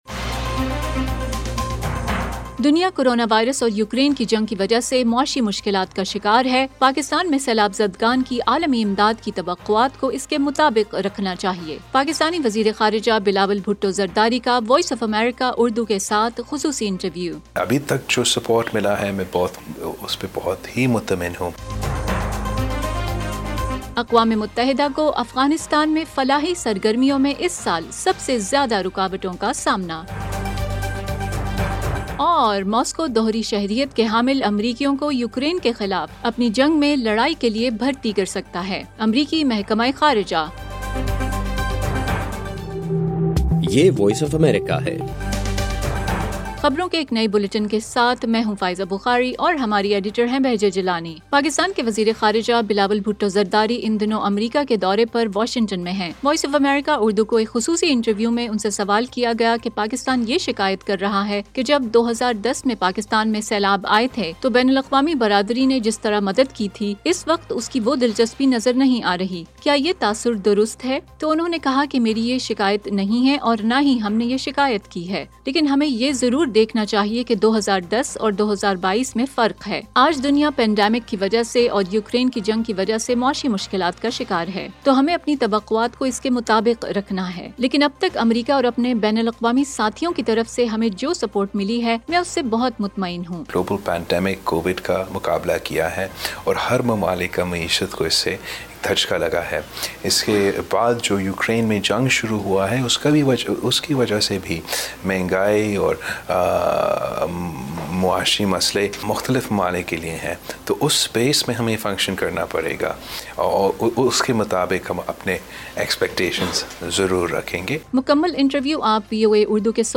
ایف ایم ریڈیو نیوز بلیٹن : رات 8 بجے